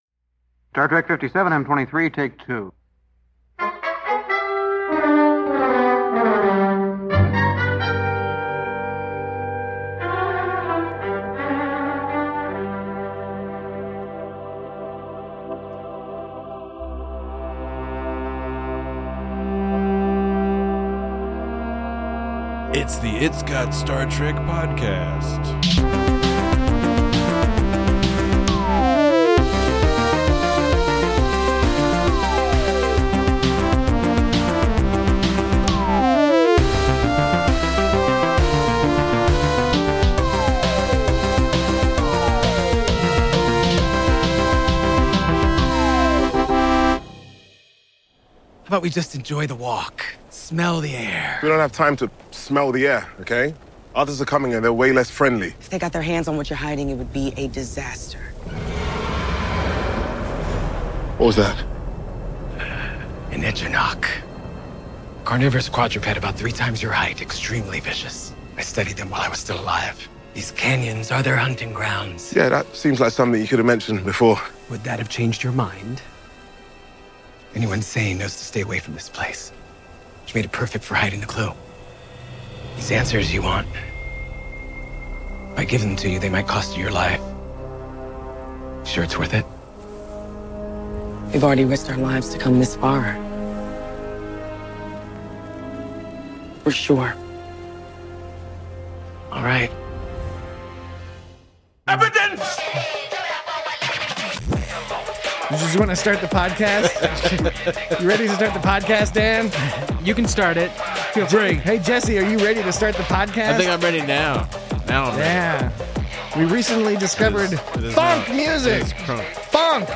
Book and Burnham team up with a worm ghost to seek out the next clue to the whereabouts of the Progenitors' technology. Join your vicious bipedal hosts as they discuss thematically connected plotlines, linear puzzle-solving, and the trials and tribulations of a gruff old man who may suffer from undiagnosed social anxiety. Also there's a lot of goofy music in this episode for some reason.